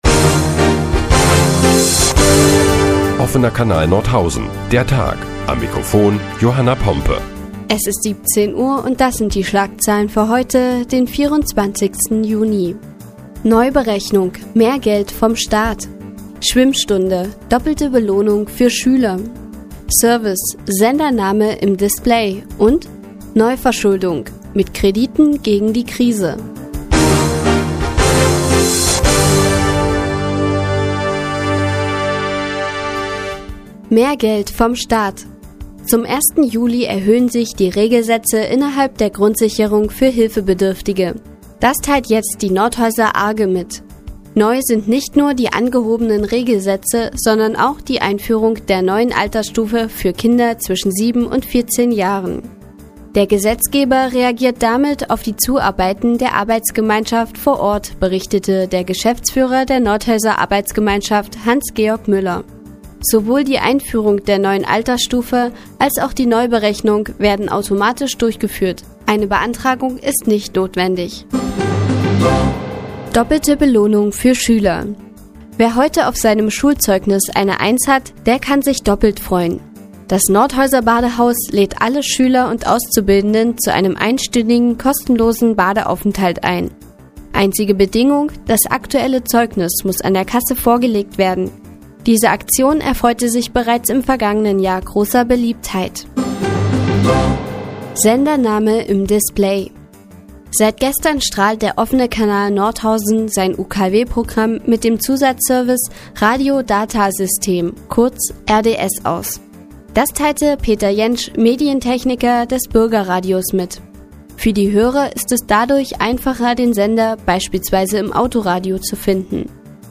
Die tägliche Nachrichtensendung des OKN ist nun auch in der nnz zu hören. Heute geht es unter anderem um mehr Geld vom Staat und doppelte Belohnung für Schüler.